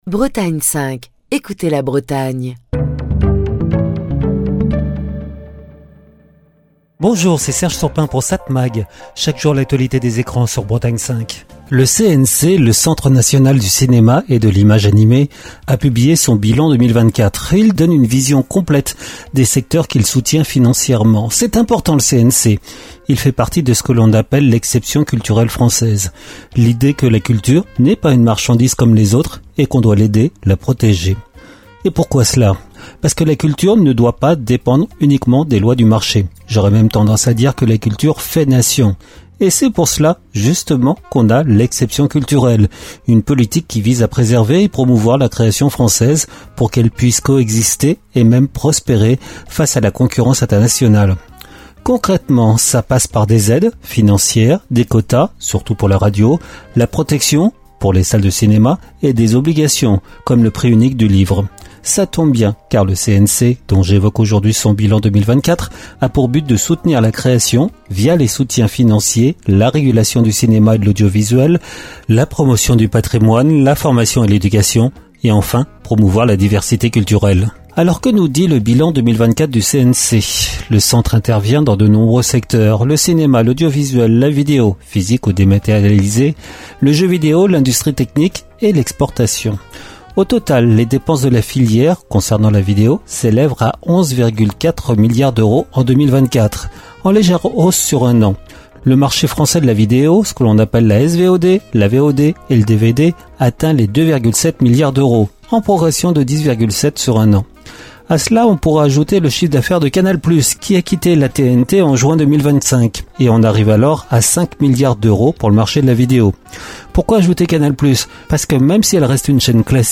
Chronique du 4 juin 2025.